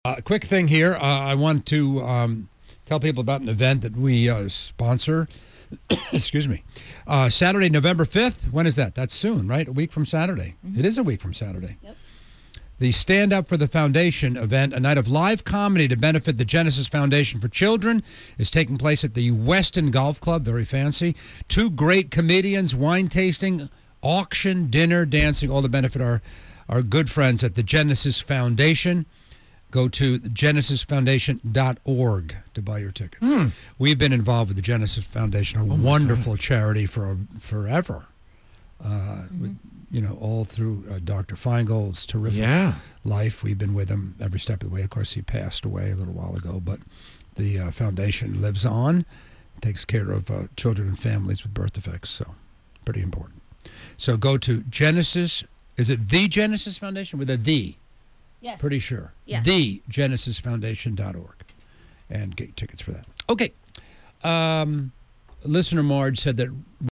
Radio Promo: KISS 108 & Matty in the Morning Show
On Wednesday October 26th, 2016, Matty in the Morning Show on KISS 108 host Matty Siegel highlighted The Genesis Foundation for Children’s upcoming comedy event. The event, Stand Up for the Foundation, will be held on November 5th 2016 at 6:00pm at the Weston Golf Club.